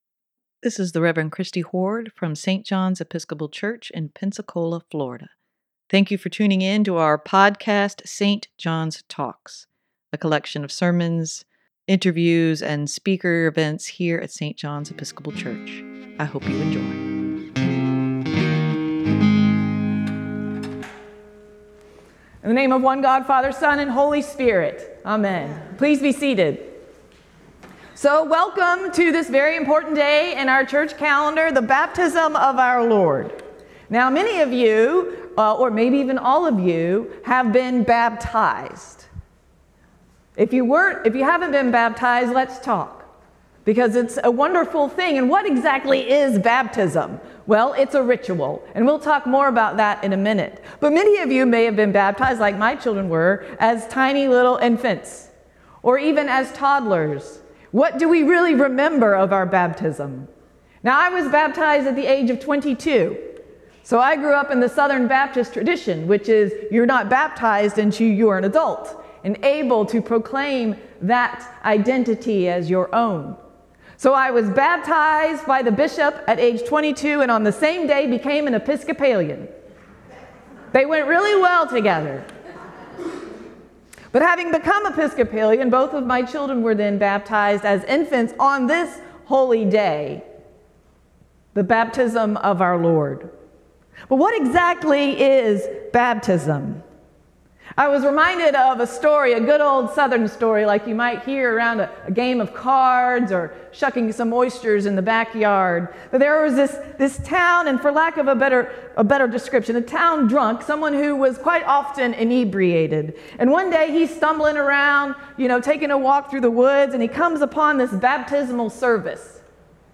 Sermon for Sunday, Jan. 8, 2023: One Percent Closer Every Day